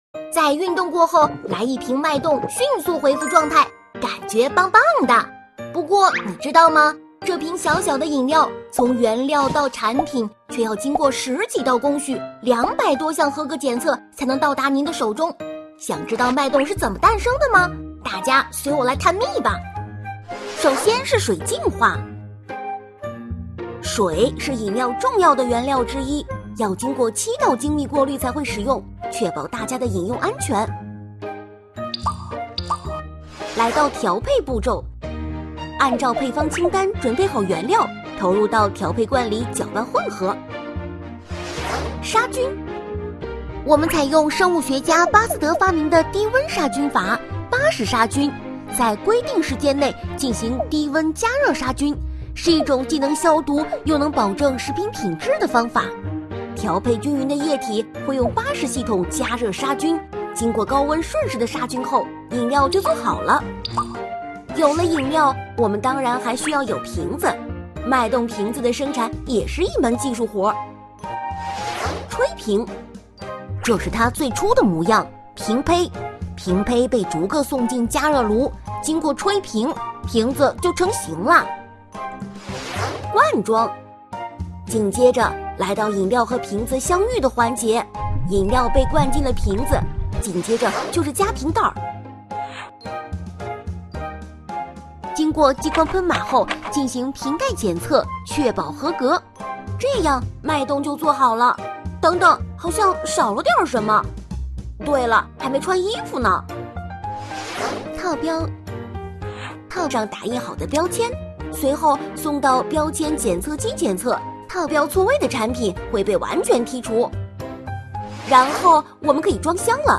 飞碟说-女4-脉动的诞生.mp3